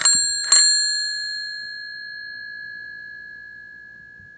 question_markTermékkör Retró csengő
Csepel acél csengő, 58 mm, krómozott
Közepes méretű, szép acél csengő